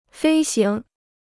飞行 (fēi xíng) Free Chinese Dictionary